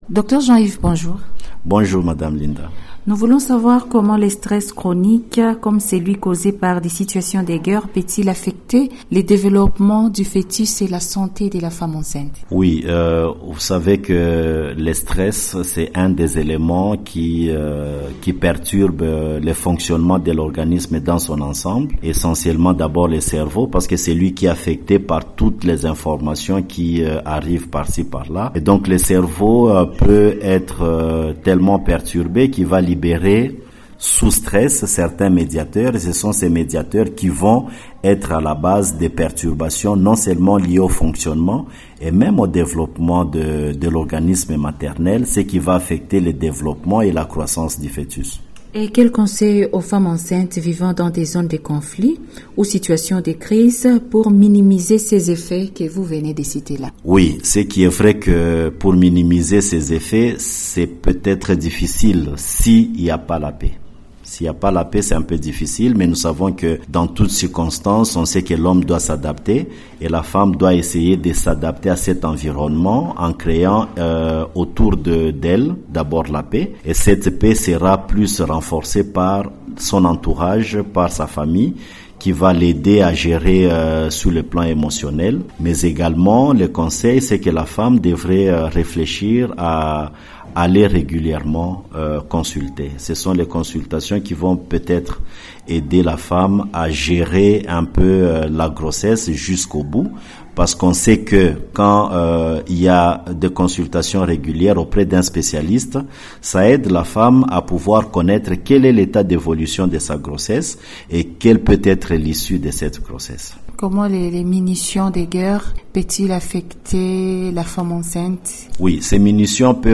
Santé
dans un entretien avec radio MAENDELEO ce Lundi 31 Mars 2025.